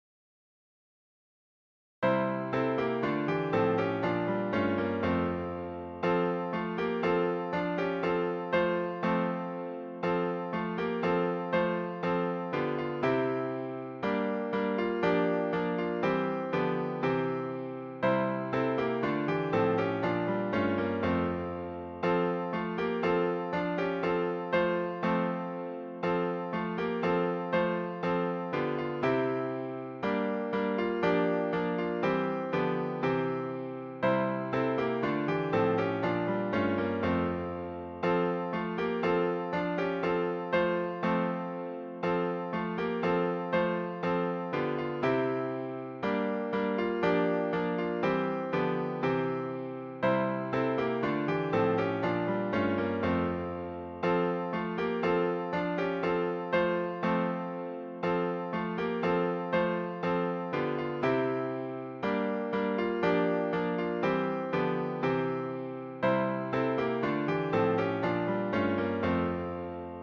伴奏
示唱
赵紫宸（见《赞美诗》第138首介绍）作词，中国曲调